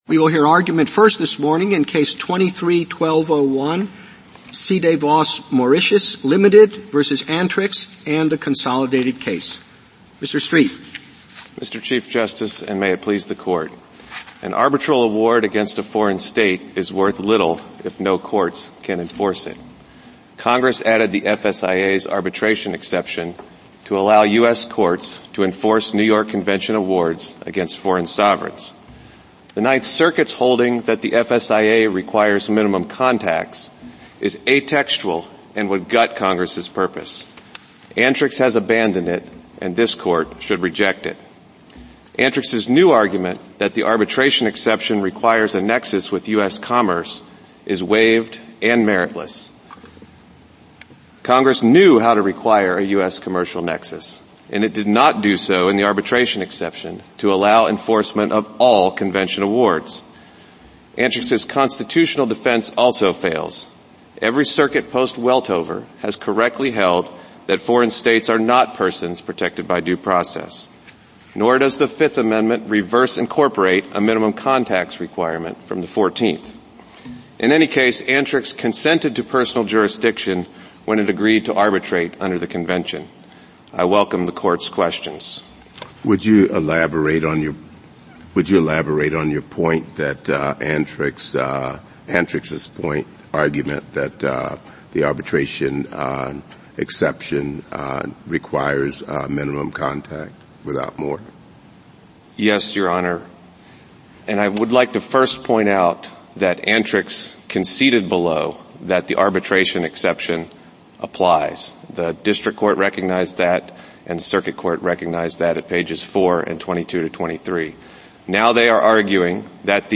Ltd. U.S. Supreme Court Oral Arguments Oyez National, Government & Organizations 4.6 • 640 Ratings 🗓 3 March 2025 ⏱ 49 minutes 🔗 Recording | iTunes | RSS 🧾 Download transcript Summary A case in which the Court held that plaintiffs need not prove minimum contacts before federal courts may assert personal jurisdiction over foreign states sued under the Foreign Sovereign Immunities Act.